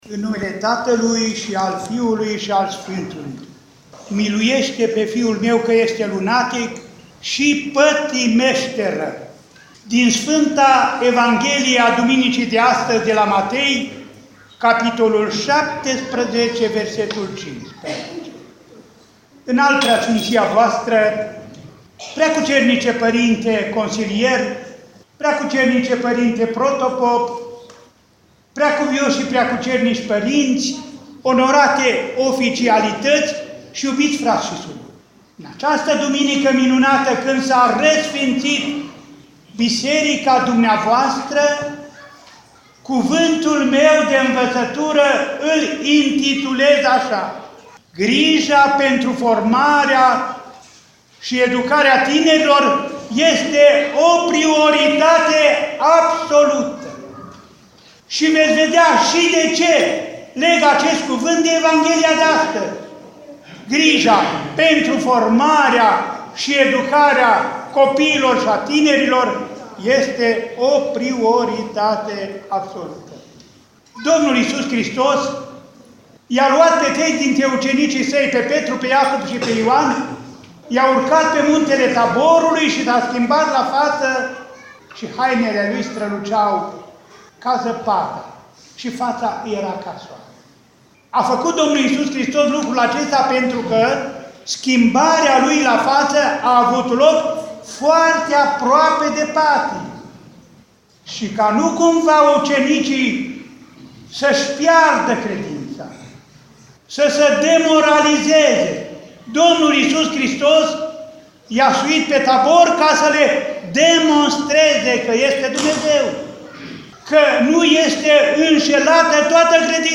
Cuvânt de învățătură rostit de Înaltpreasfințitul Părinte Andrei, Mitropolitul Clujului în parohia Agriș, Protopopiatul Turda.
aug. 13, 2017 | Predici IPS Andrei